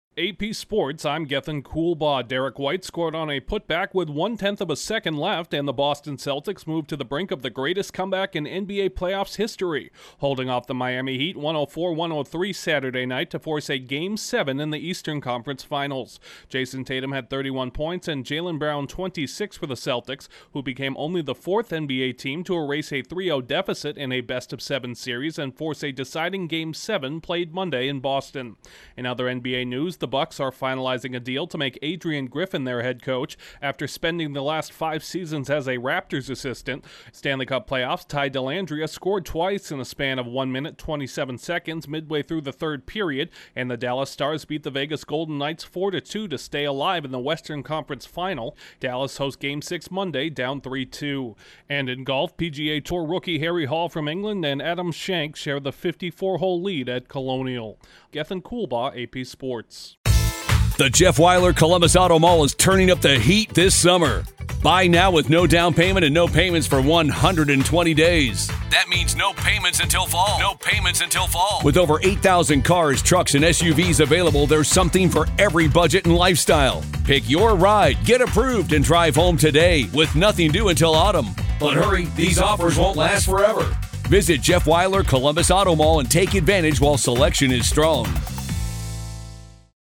AP News Summary at 10:47 a.m. EDT